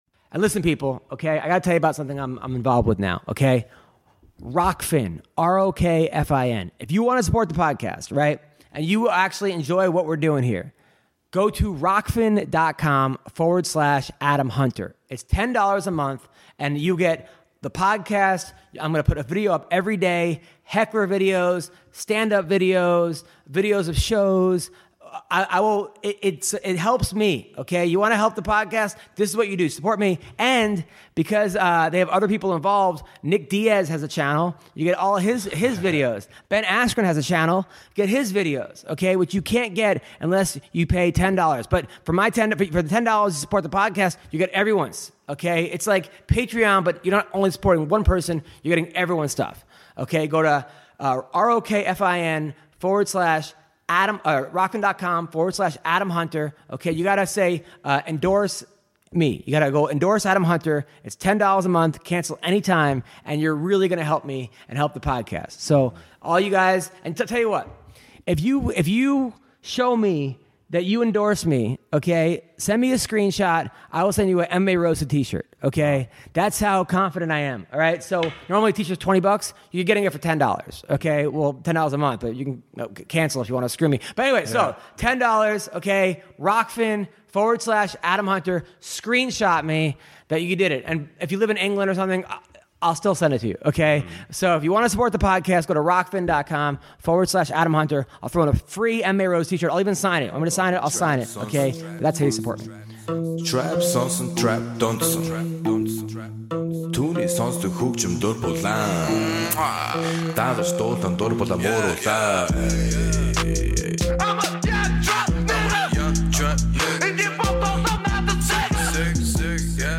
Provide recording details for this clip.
joined in studio